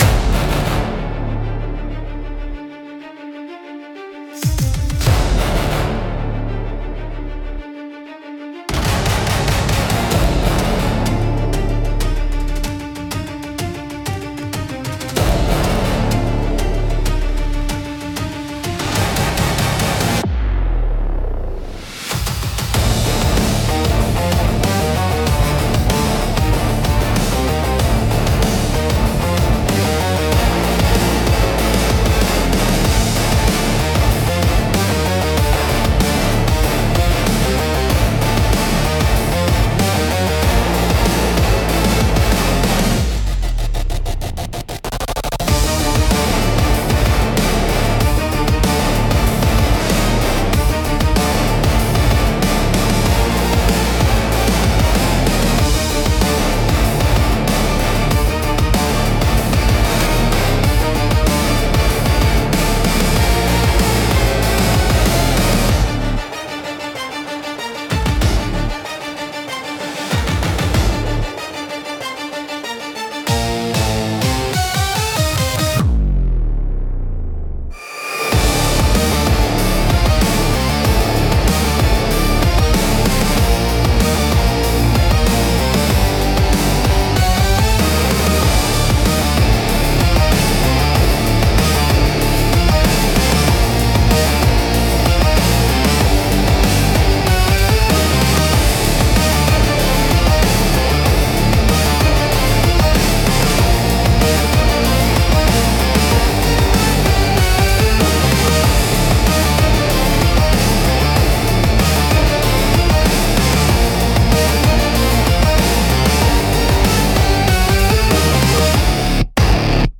聴く人の注意を引きつけ、場の緊張感を高める効果があり、ドラマチックで切迫した演出に寄与します。